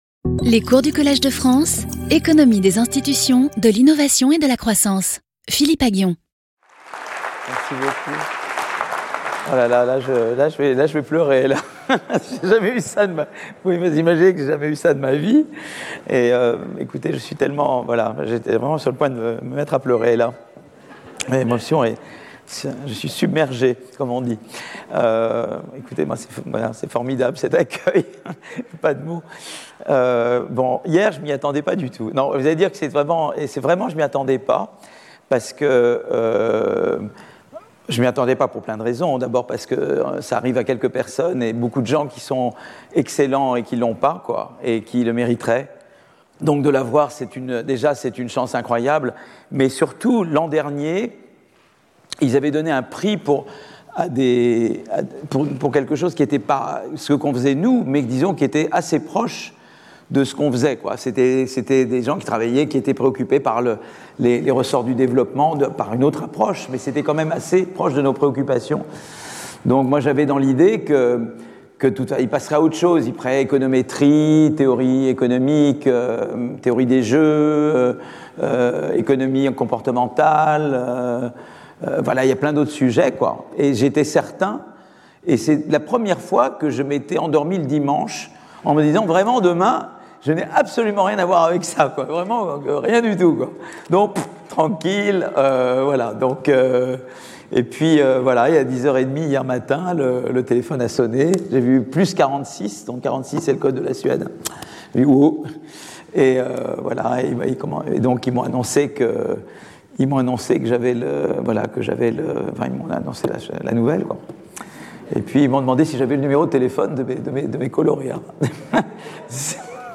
Speaker(s) Philippe Aghion Professor at the Collège de France
Lecture